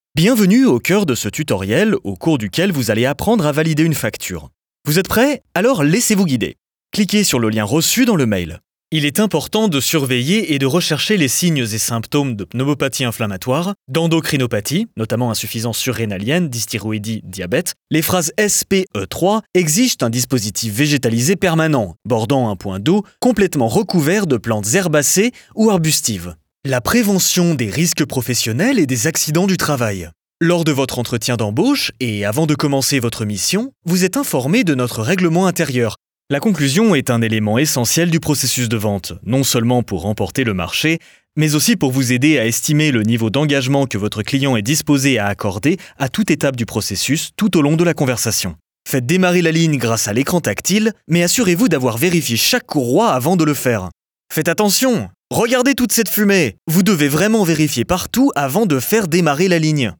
Natural, Versátil, Seguro, Amable, Empresarial
E-learning
Su voz natural, de tono medio-grave, suena profesional y confiable, pero también cálida y amigable, ideal para contenido corporativo y educativo.